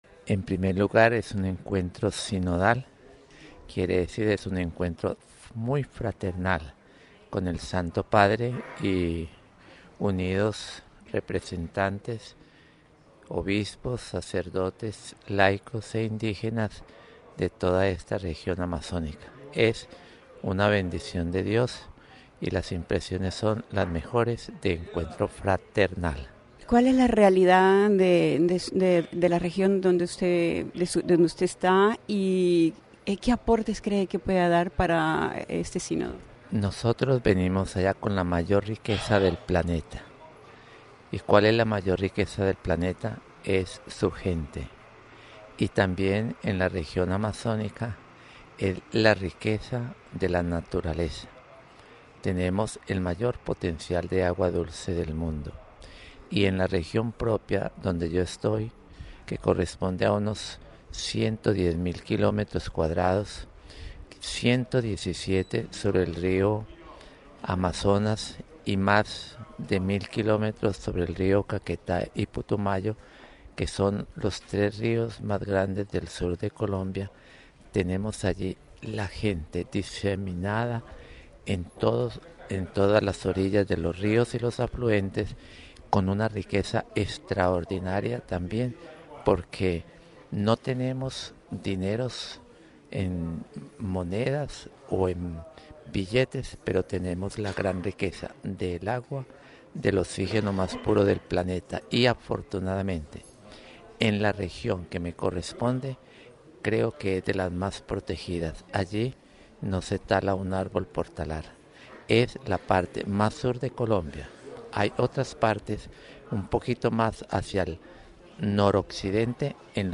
Mons. Modesto González, obispo Guasdualito, Venezuela
En cambio, Mons. José de Jesús Quintero Díaz, Vicario Apostólico de Leticia en Colombia, nos dio sus impresiones sobre este encuentro sinodal, y sobre todo, habló de su realidad, de la riqueza que representa su región, riqueza, humana y natural, donde se preserva la naturaleza, es la región más protegida de Colombia.